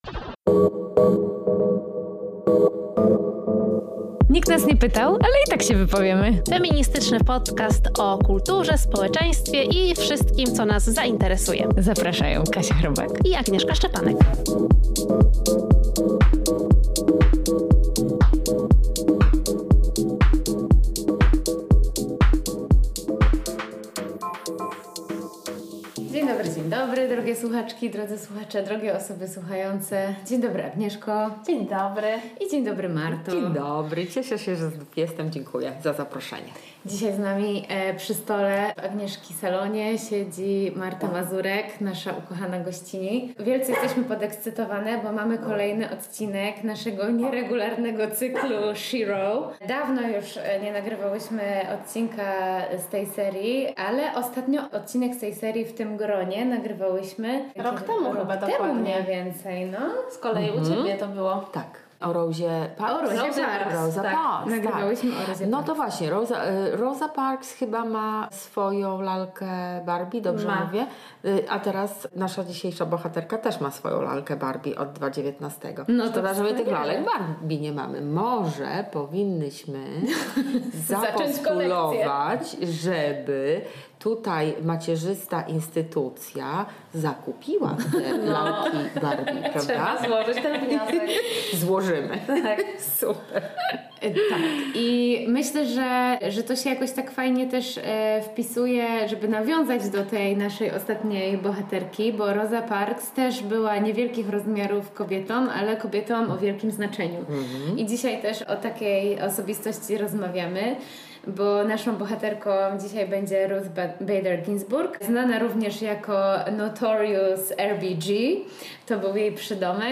… continue reading 117 에피소드 # Społeczeństwo # Estrada Poznańska # Kobiety # Kultura # Feminizm # Women # Girlpower # Książki # Rozmowy # Sztuka